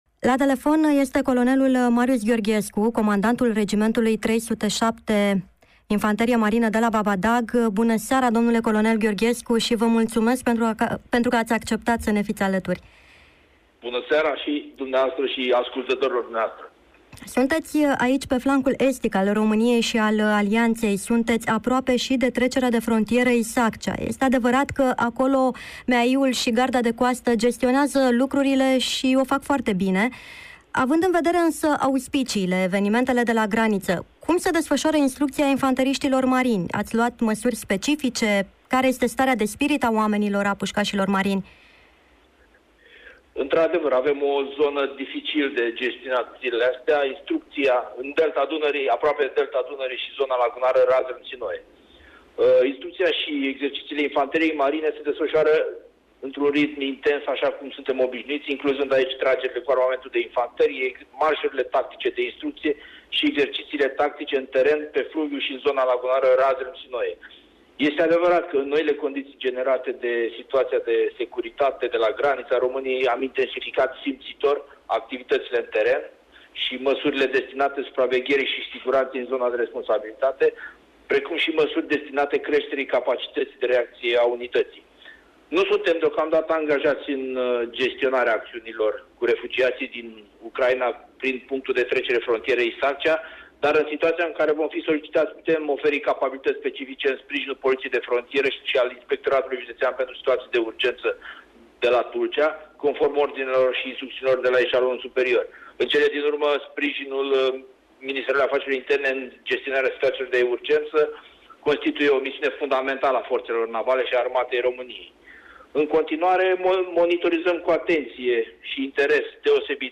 Prezent prin telefon la emisiunea Jurnal Militar Scutul Dobrogei